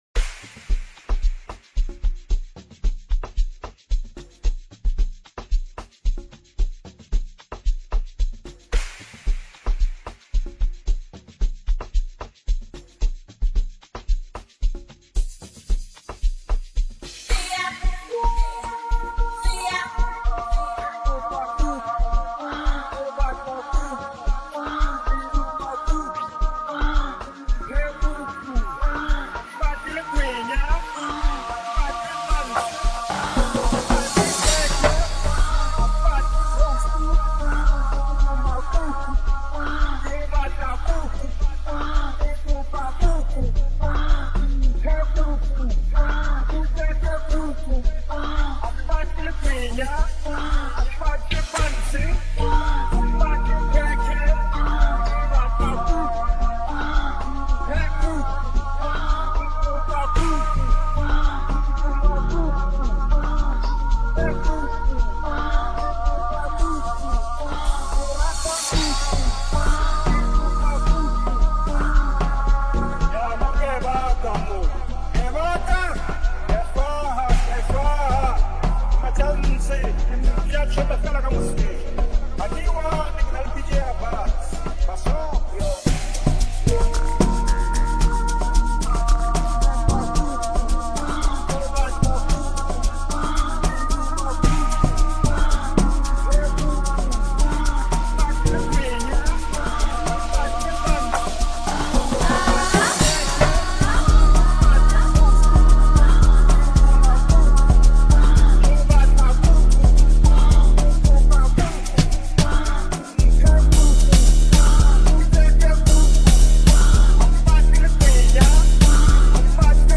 Sgija/Amapiano genre